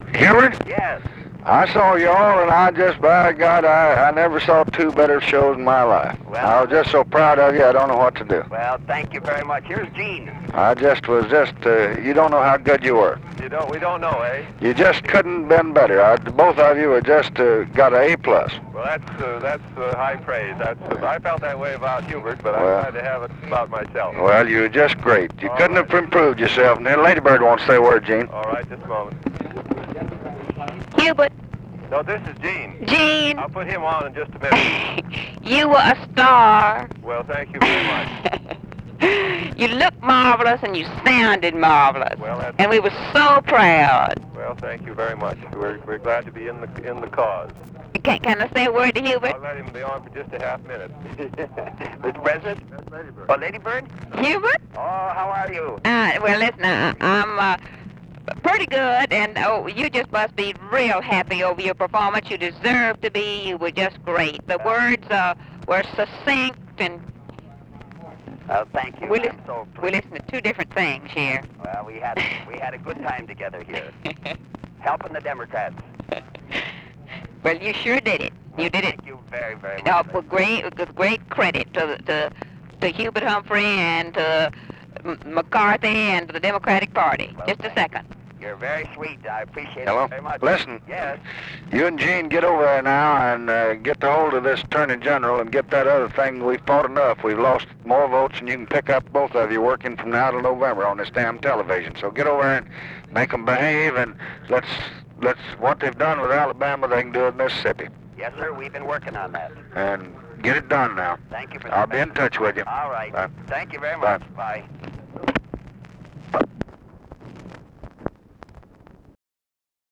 Conversation with HUBERT HUMPHREY, EUGENE MCCARTHY and LADY BIRD JOHNSON, August 23, 1964
Secret White House Tapes